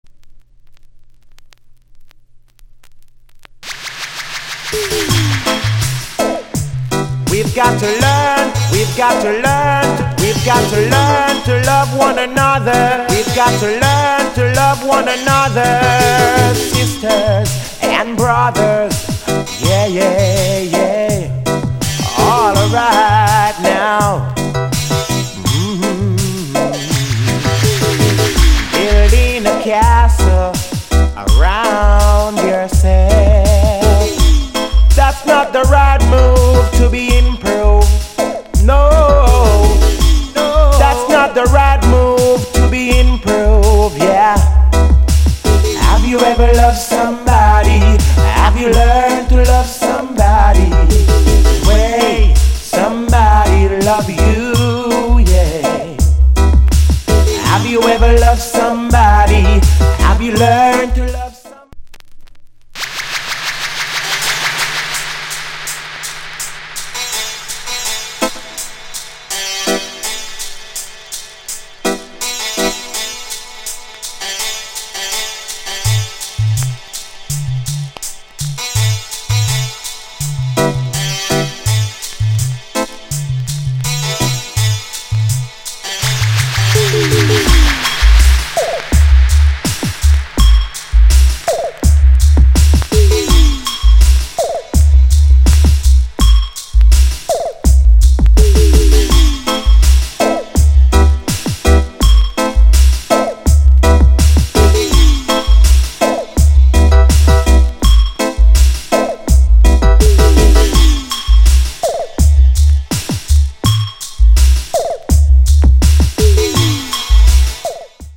* ダヴィーでチープなデジタル・ダンスホール。